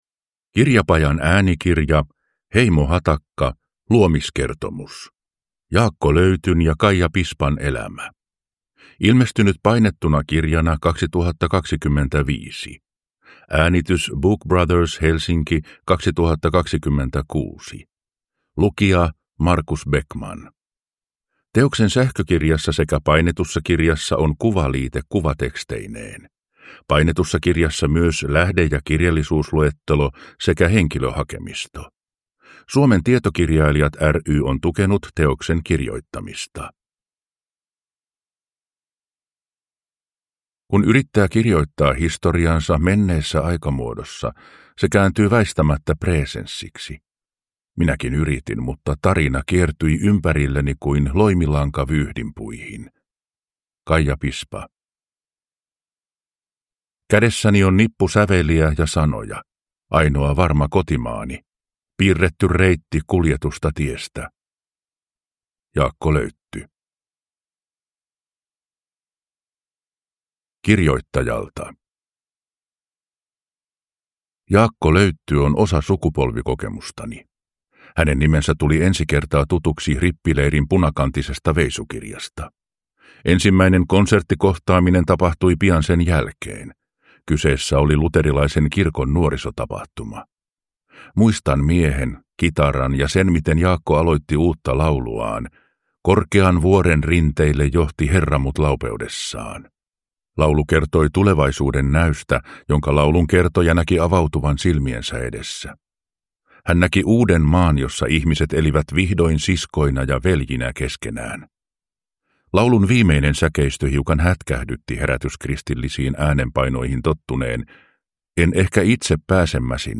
Luomiskertomus – Ljudbok